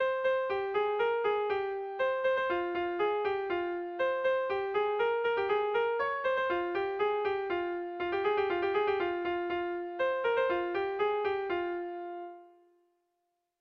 Erromantzea
ABAB